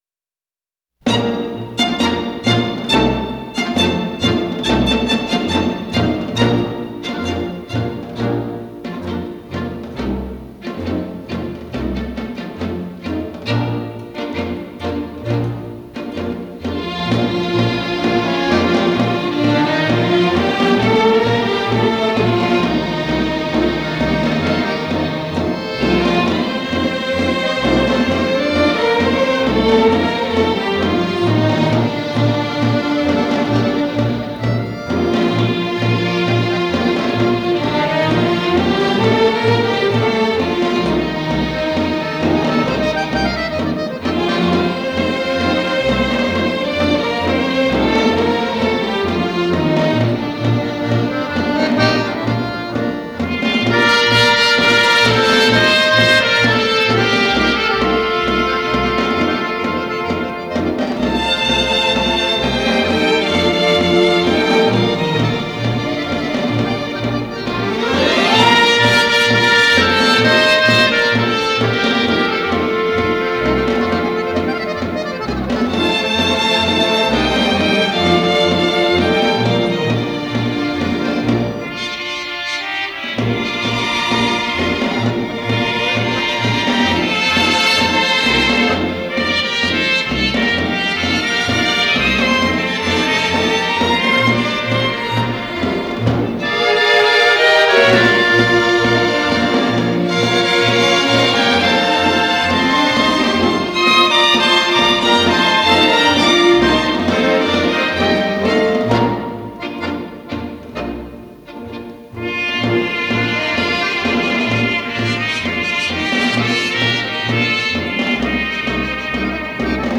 Genre: Tango, Latin